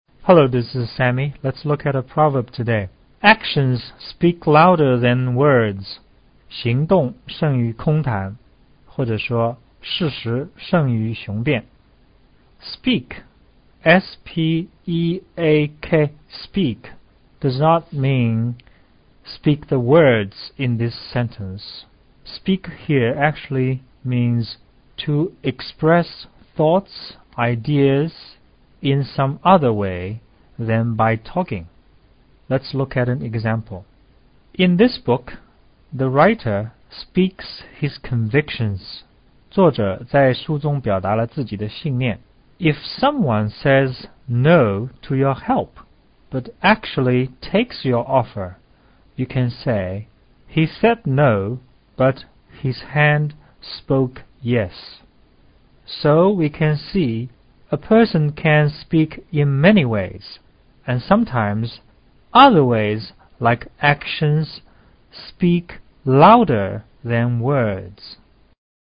【世博精解】